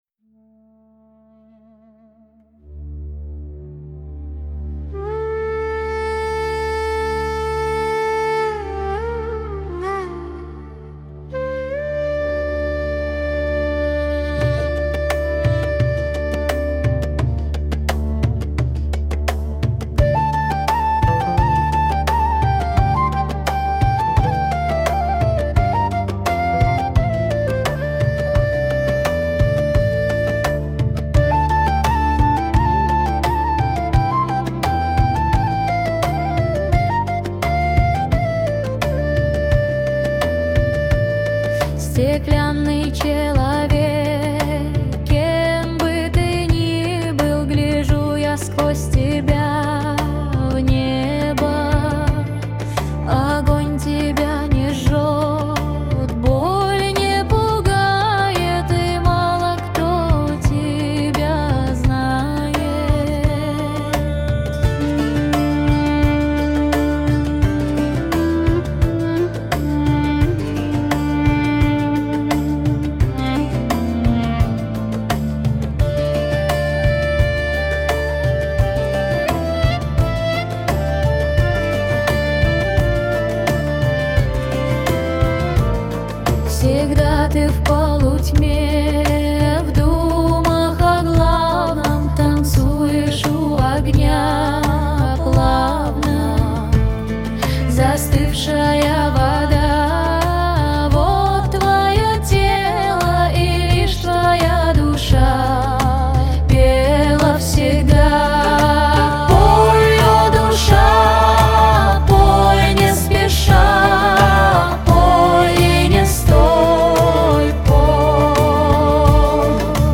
• Жанр: AI Generated